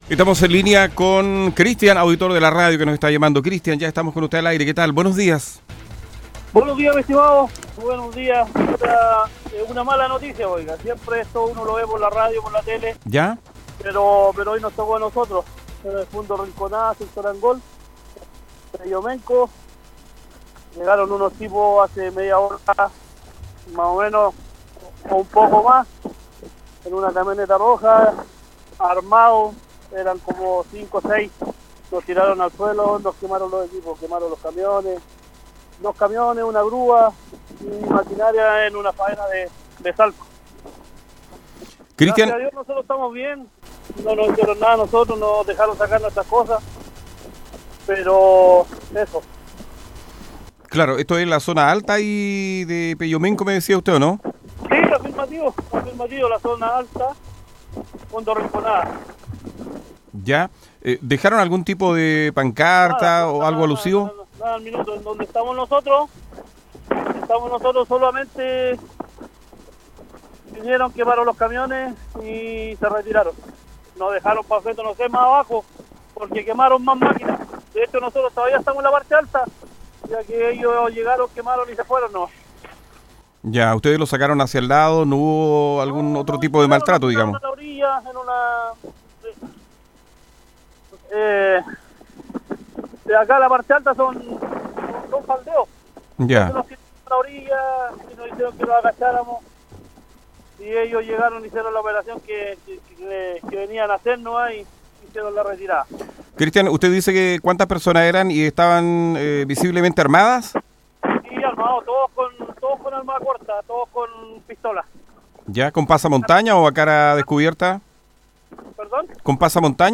A eso de las 09:00 horas de este viernes, una de las personas afectadas se comunicó con Radio Bío Bío de Los Ángeles para relatar el actuar de los encapuchados, ya que no se lograron contactar con las policías.